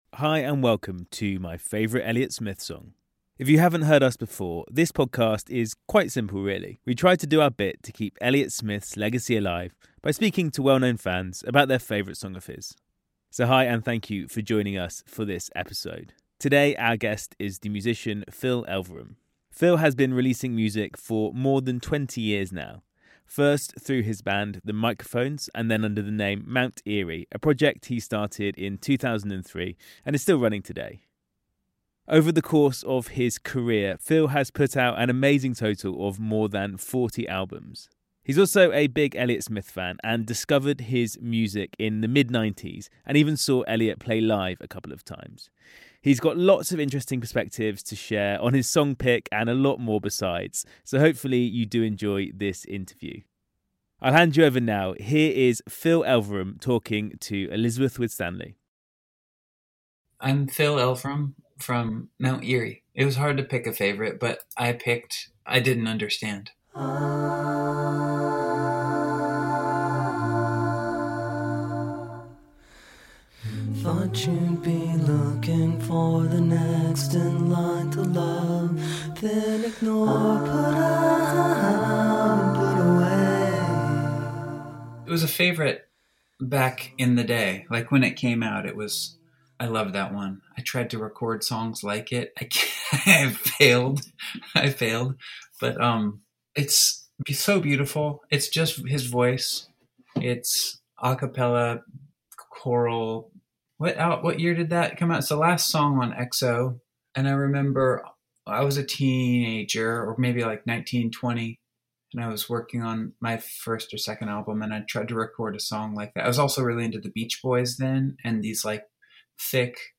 Phil discovered Elliott Smith’s music in the mid-'90s and even saw him play live a couple of times. He has lots of interesting perspectives to share - so hopefully you enjoy this interview!